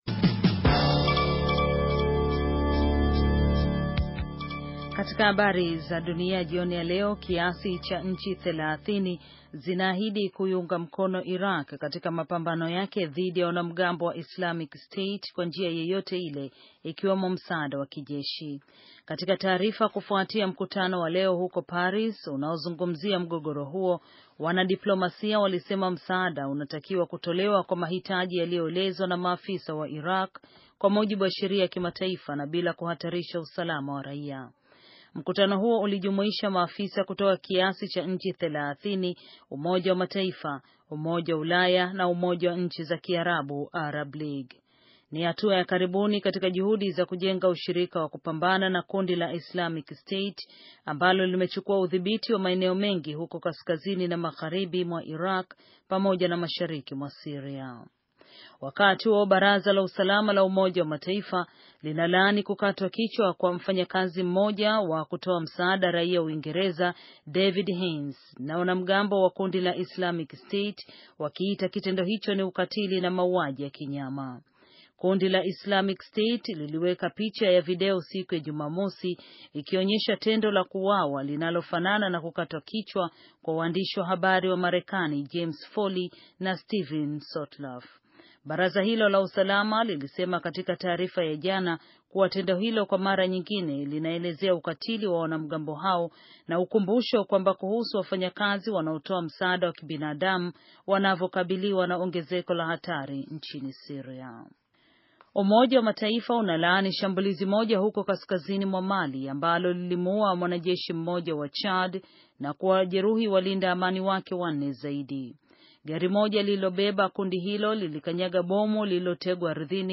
Taarifa ya habari - 6:54